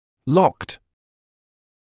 [lɔkt][локт]заклиненный; заблокированный